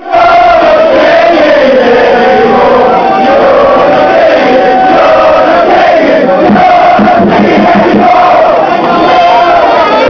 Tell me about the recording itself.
Chants from the match against Orient Quality is pretty rough but if you were there they will probably put a smile on your face, if you weren't you will probably wish you were!